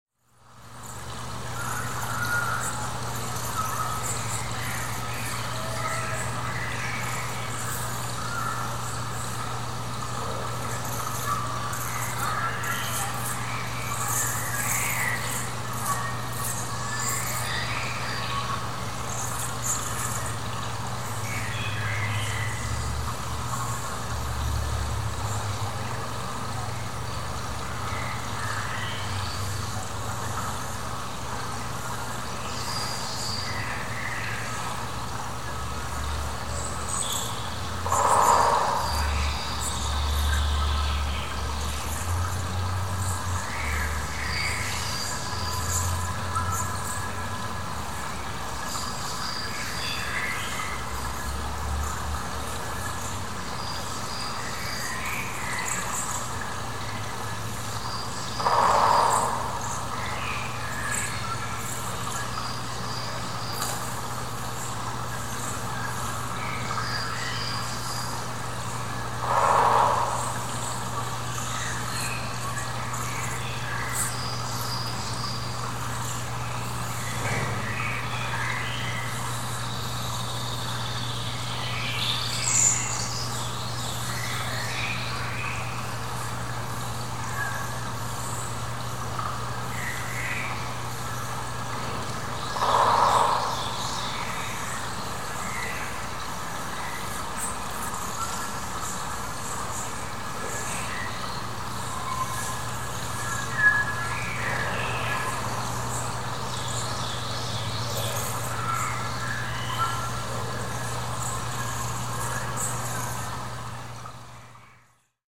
JRBP (Searsville Lake) instrumentation:
• live audio feed
• 8 channel speaker array
• audio capture and sensing package